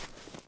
update snow step sounds
snow_3.ogg